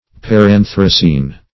Search Result for " paranthracene" : The Collaborative International Dictionary of English v.0.48: Paranthracene \Par*an"thra*cene\, n. [Pref. para- + anthracene.]